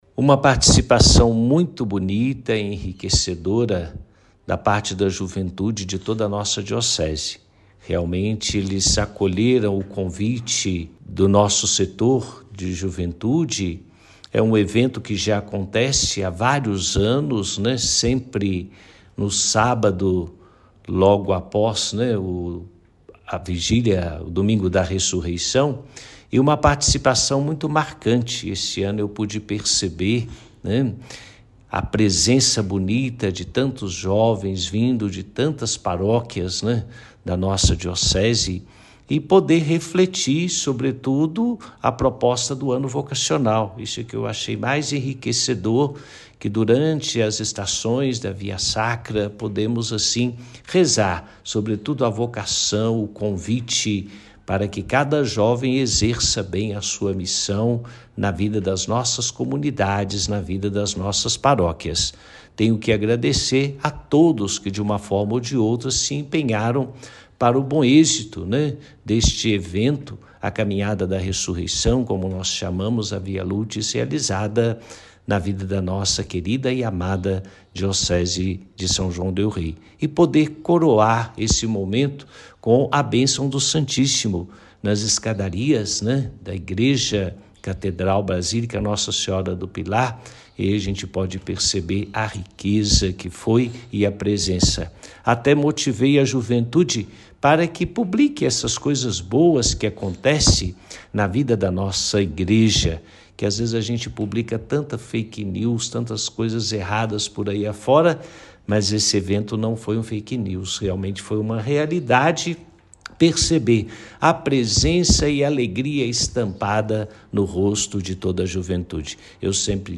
Confira a fala do bispo, na íntegra:
Dom-Jose-Eudes-fala-sobre-a-experiencia-da-Via-Lucis-2023.mp3